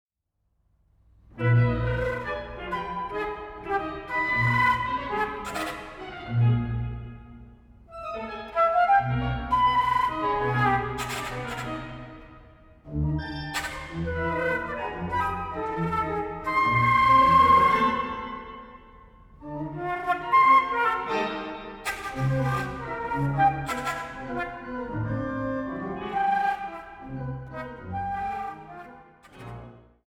Neue Musik für Flöte und Orgel (II)
Flöte
Orgel